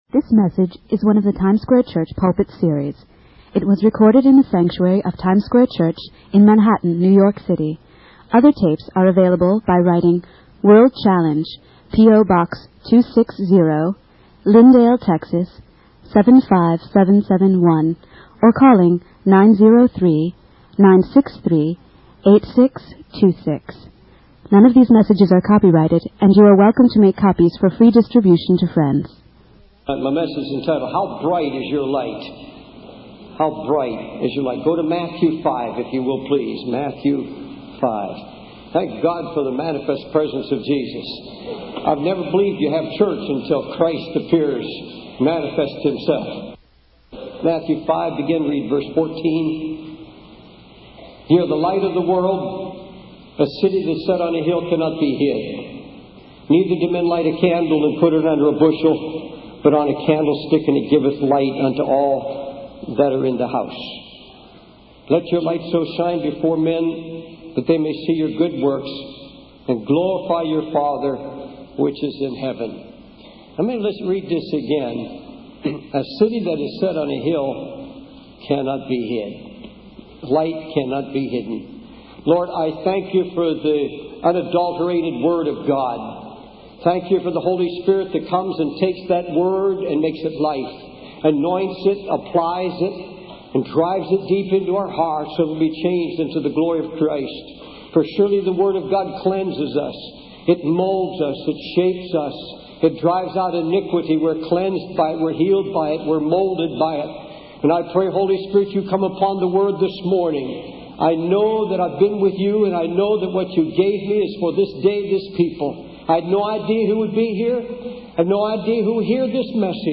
In this sermon, the preacher emphasizes the darkness and despair that exists in the world.